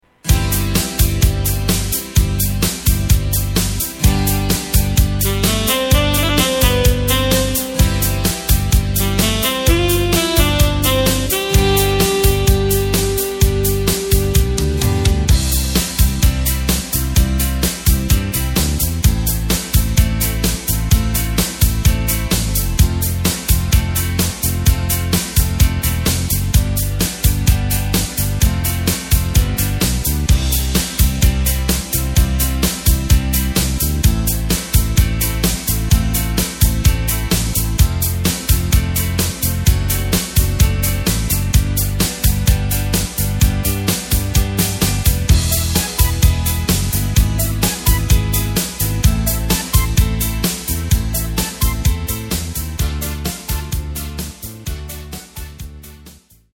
Takt:          4/4
Tempo:         128.00
Tonart:            C
Austropop aus dem Jahr 1985!
Playback mp3 Demo